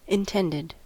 Ääntäminen
Synonyymit purpose Ääntäminen : IPA : /ɪn.ˈtɛnd.ɪd/ US : IPA : [ɪn.ˈtɛnd.ɪd] Haettu sana löytyi näillä lähdekielillä: englanti Intended on sanan intend partisiipin perfekti.